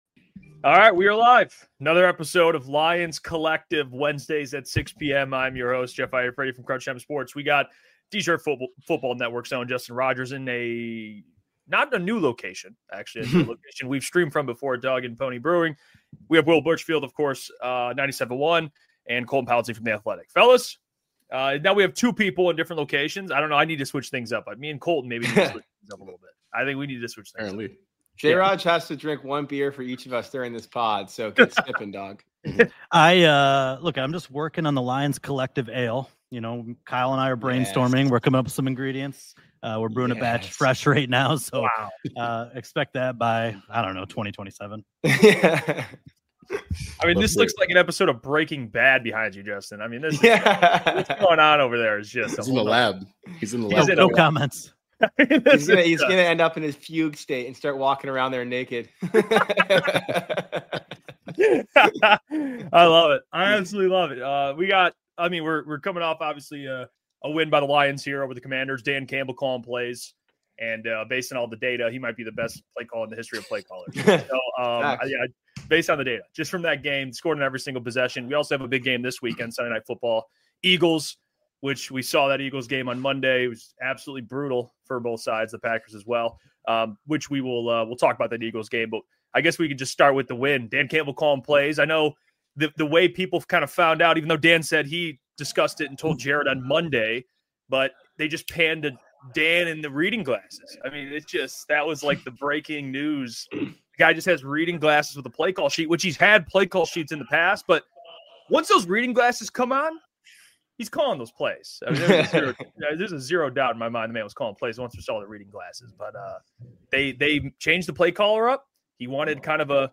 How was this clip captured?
Make sure to subscribe to Lions Collective for new roundtable episodes every week, live, on Wednesday at 6 pm ET You can also hear every episode of Lions Collective wherever you get your podcasts!